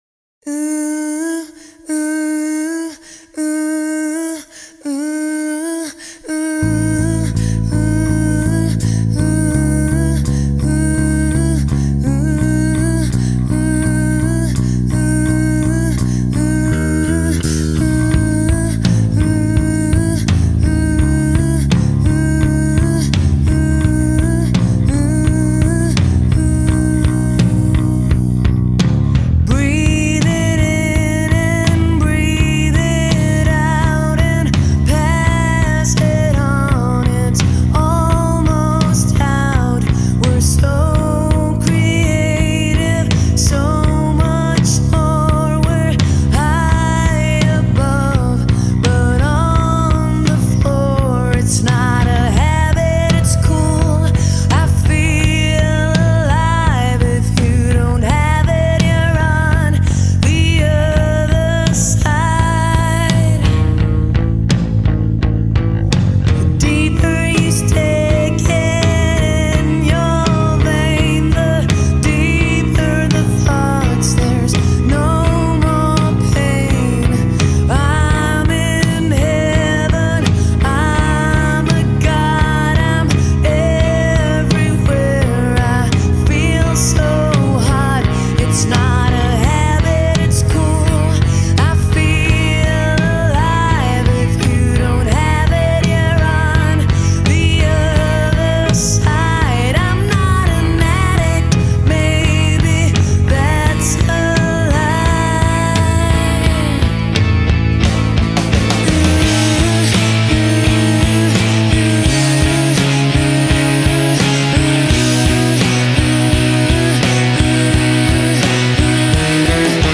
Alternative Rock, Pop Rock